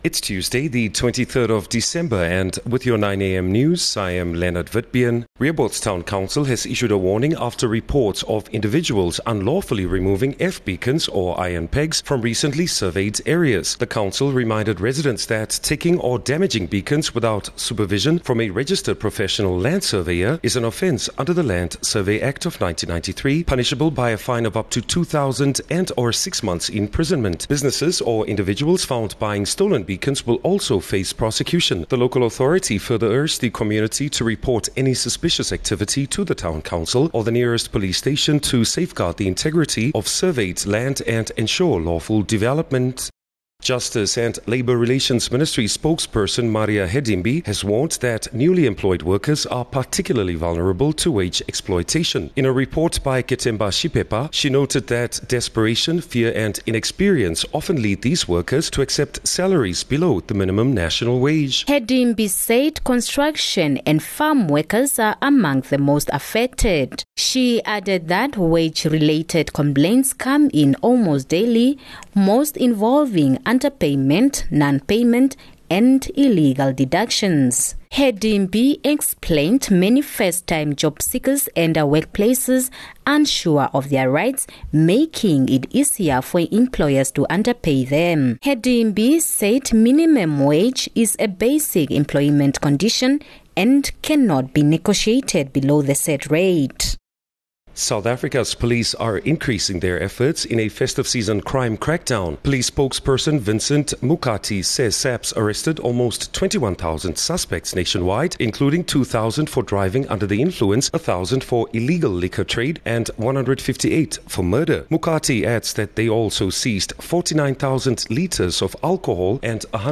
Daily bulletins from Namibia's award winning news team. Independent, Accurate, and On-Time